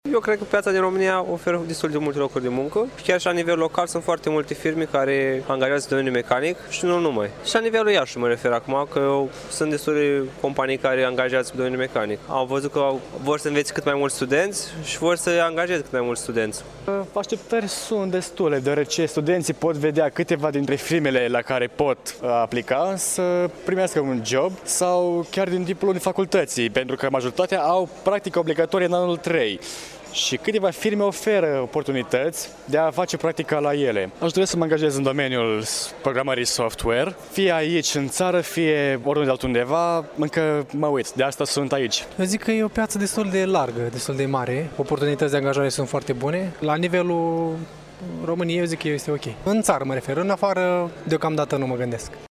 Târgul se desfăşoară în holul Facultăţii de Chimie şi sunt prezente 24 de firme.
Studenții prezenți la eveniment sunt de părere că piața muncii oferă destule oportunități:
7-nov-rdj-12-vox-studenti.mp3